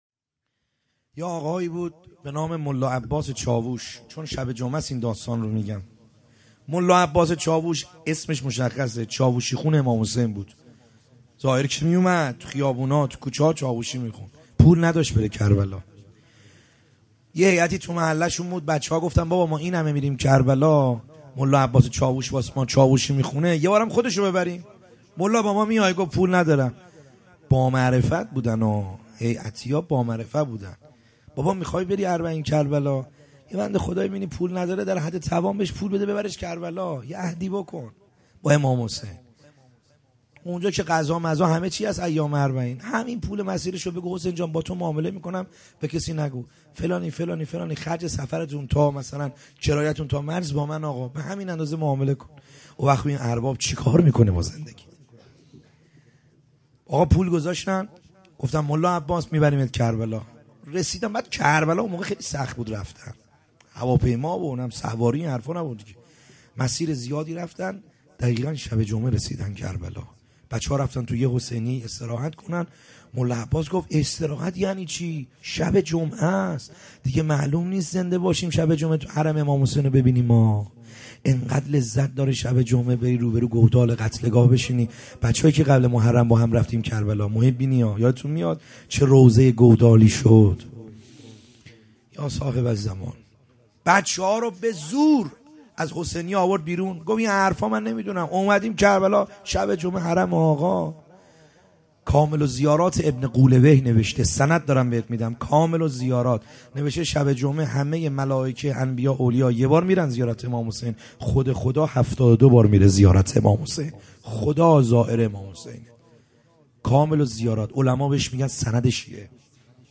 خیمه گاه - بیرق معظم محبین حضرت صاحب الزمان(عج) - روضه | حضرت علی اکبر علیه السلام